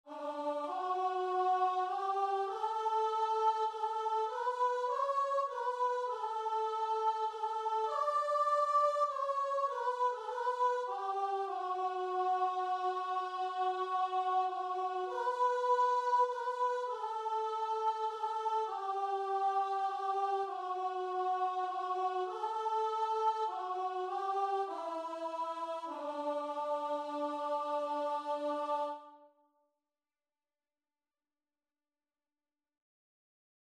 6/4 (View more 6/4 Music)
Christmas (View more Christmas Guitar and Vocal Music)